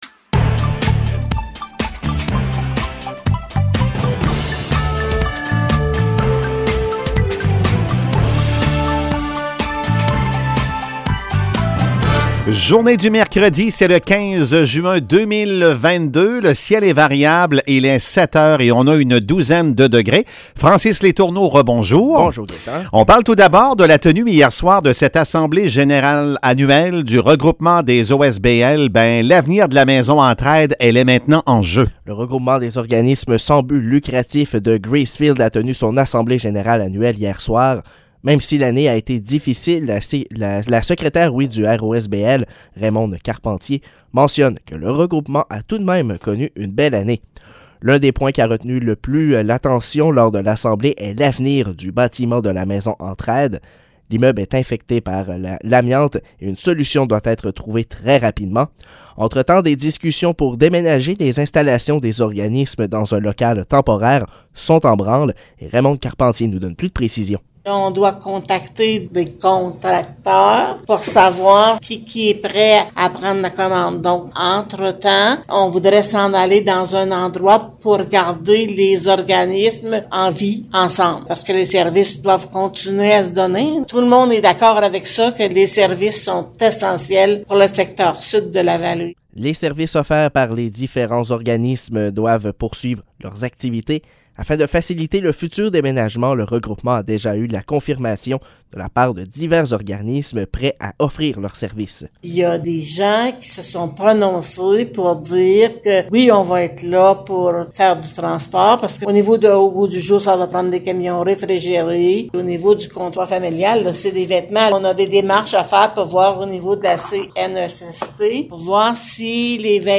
Nouvelles locales - 15 juin 2022 - 7 h